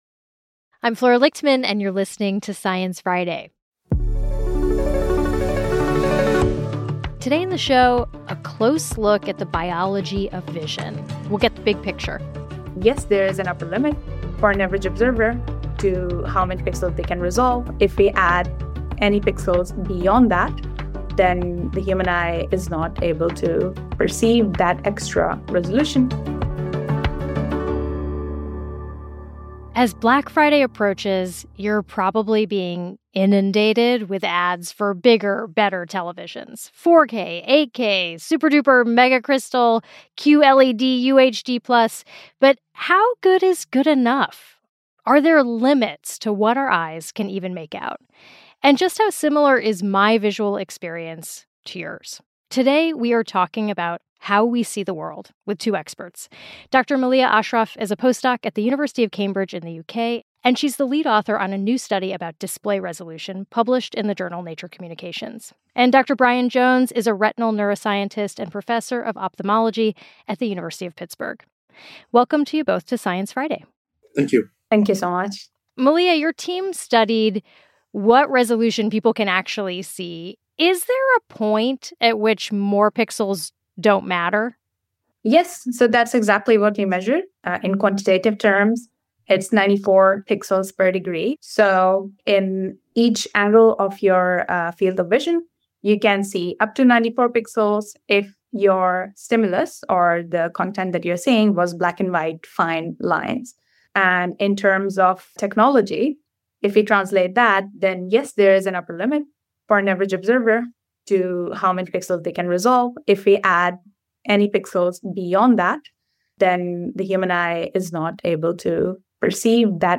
It starts at the beginning of a day's shearing, with sounds of sheep in the yards, dogs barking and shepherd's whistling.